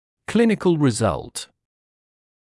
[‘klɪnɪkl rɪ’zʌlt][‘клиникл ри’залт]клинический результат